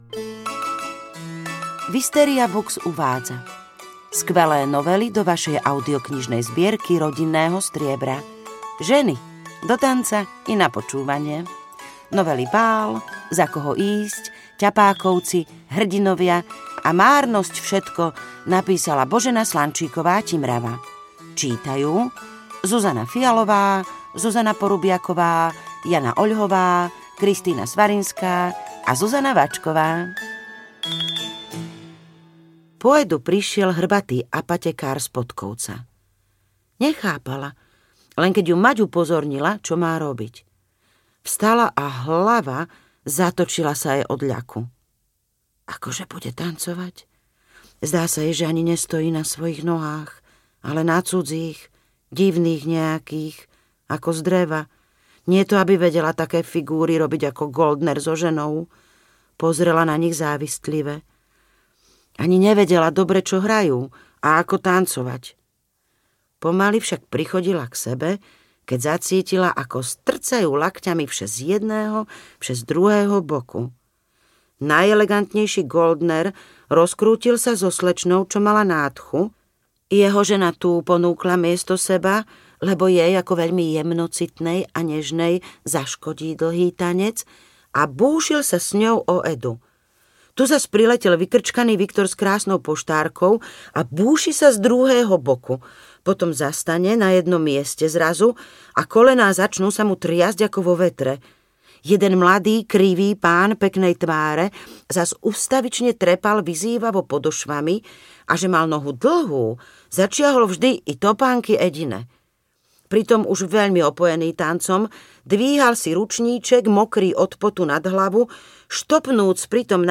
Ženy. Do tanca i na počúvanie audiokniha
Ukázka z knihy